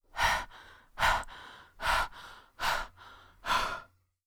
breath-female2.wav